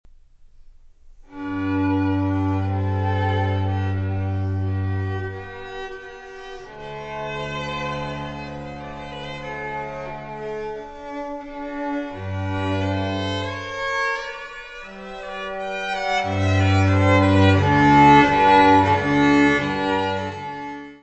Quintets VII & VIII for string quartet & guitar; Gran quintetto, op. 65
violino
guitarra
Área:  Música Clássica